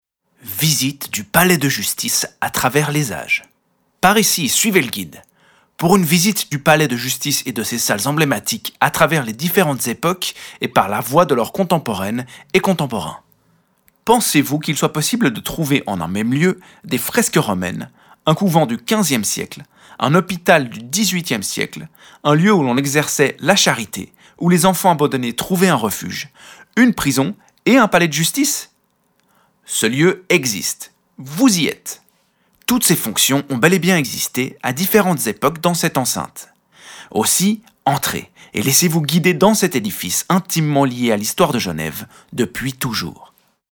Pour une visite du Palais de justice et de ses salles emblématiques à travers les différentes époques et par la voix de leurs contemporaines et contemporains.
visite-historique-palais-justice-introduction.mp3